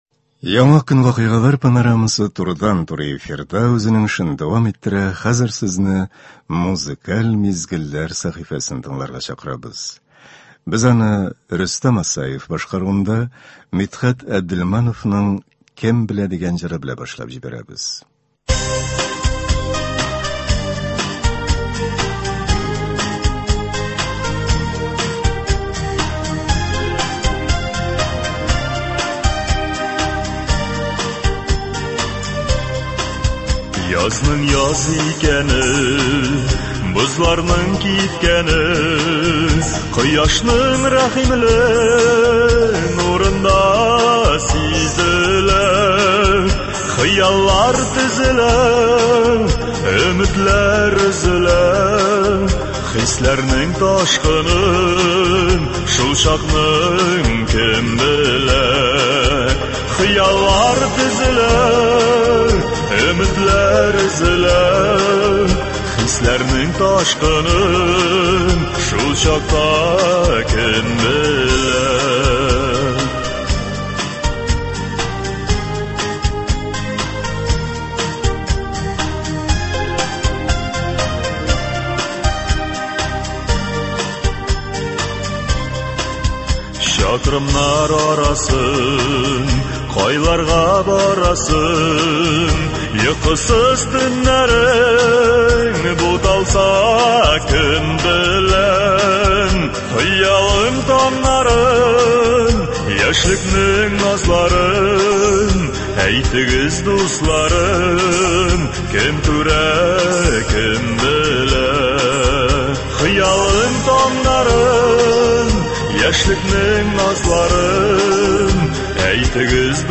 Концерт.